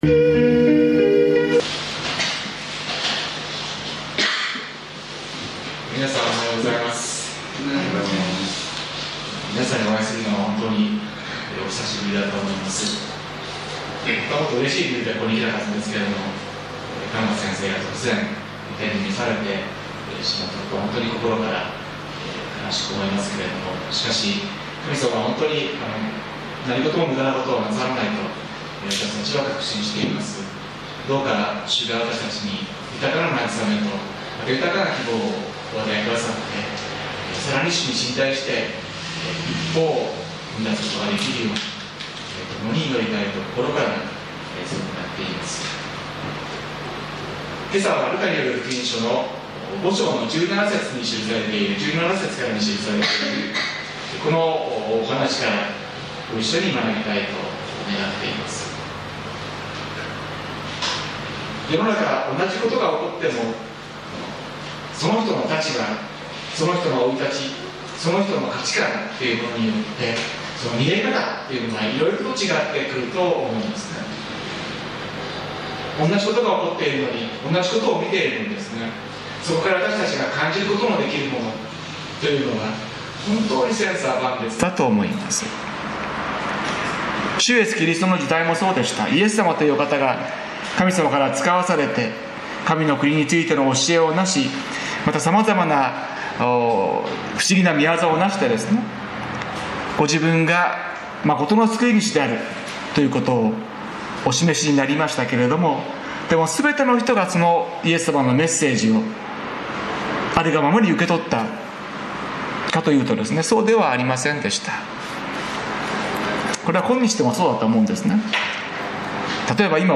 きょう、驚くべきことを見た 宇都宮教会 礼拝説教